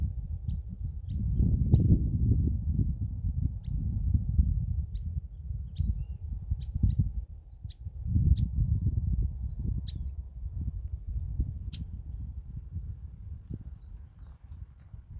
Myiodynastes maculatus solitarius
Nombre en inglés: Streaked Flycatcher
Condición: Silvestre
Certeza: Observada, Vocalización Grabada